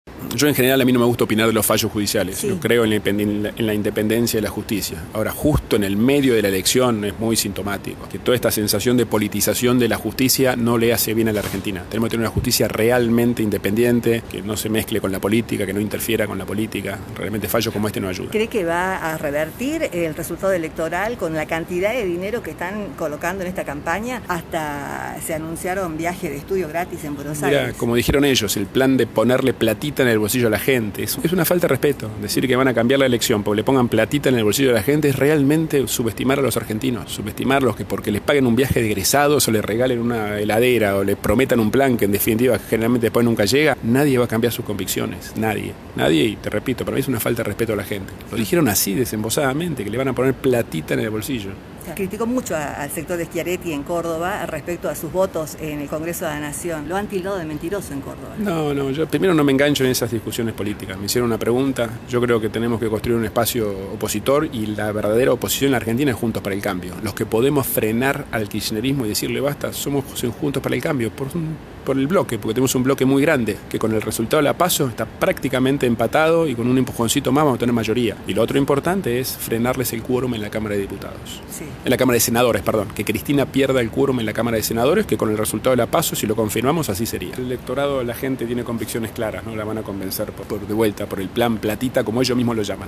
Tras el rechazo que causó en el schiarettismo su contestación, Larreta se defendió en diálogo con Cadena 3 y apuntó: "No me engancho con las discusiones políticas, sólo me hicieron una pregunta".